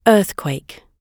Earthquake /ˈɜːθkweɪk/
earthquake__gb_2.mp3